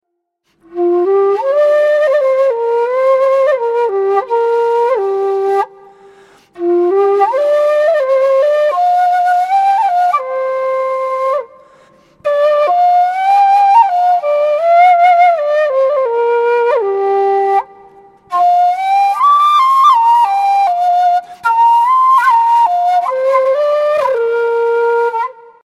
Кена (Quena, Ramos, F) Перу
Кена (Quena, Ramos, F) Перу Тональность: F
Материал: тростник